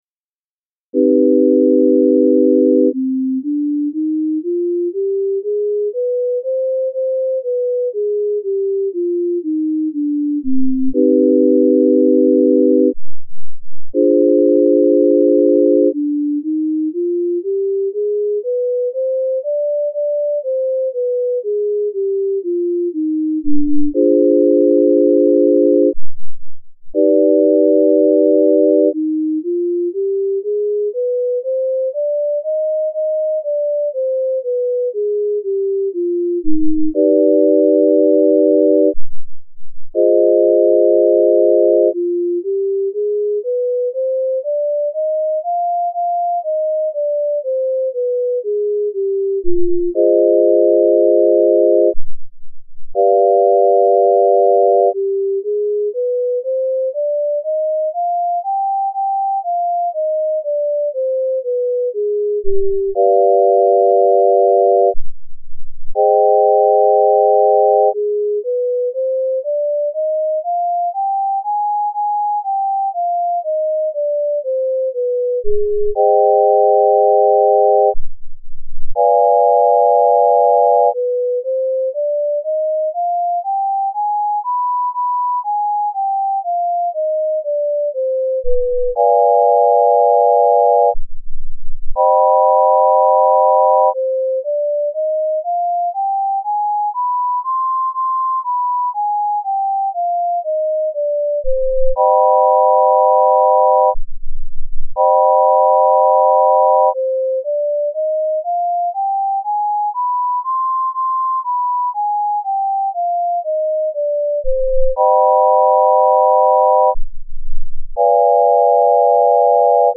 C-Harmonic-Minor-Tempered
C-Harmonic-Minor-Scale Progression Using the Tempered Scale
music02_008_Harmonic-Minor_Temp.mp3